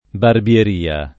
barbieria [ barb L er & a ]